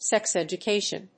音節séx educàtion